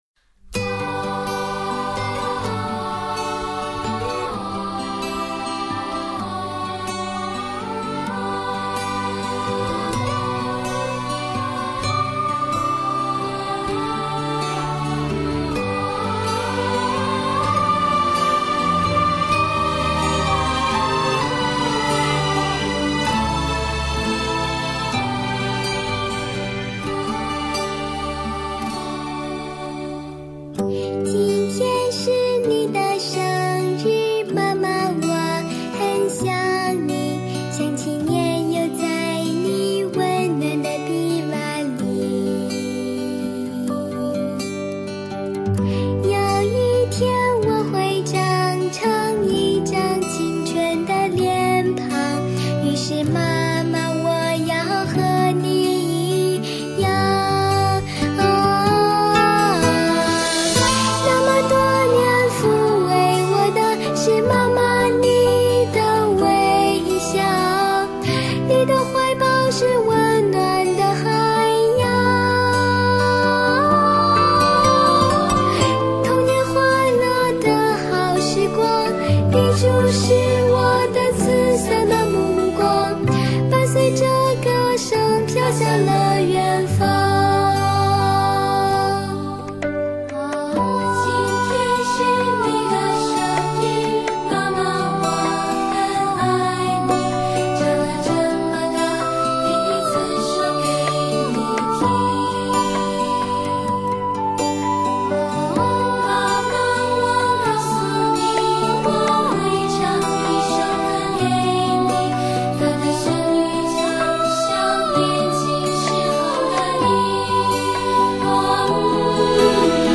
[20/11/2017]童声合唱《今天是你的生日，妈妈》